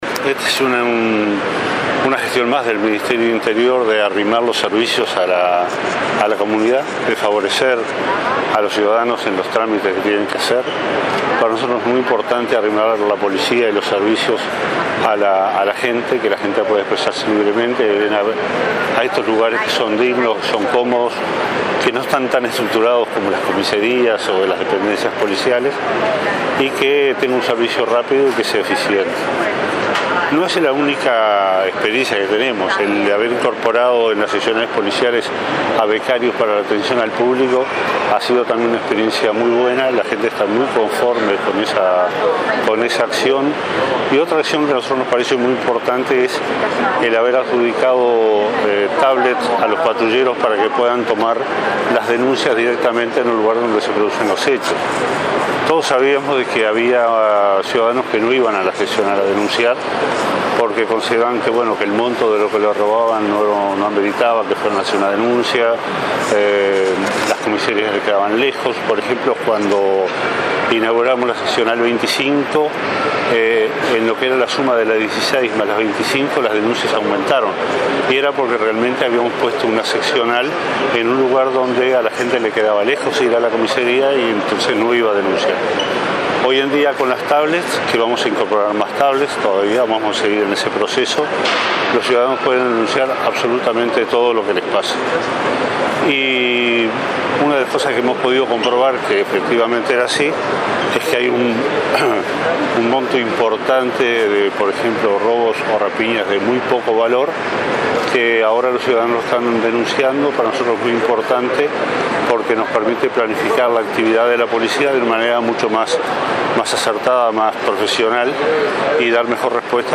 El ministerio del Interior inauguró un local para expedición de certificado de antecedentes judiciales en el Mercado Agrícola de Montevideo. Este local, junto a la adjudicación de un total de 2.800 tabletas a patrulleros de la capital y el ingreso de becarios para la atención al público, son parte de la política de acercar los servicios policiales a la comunidad, dijo el subsecretario del Interior, Jorge Vázquez.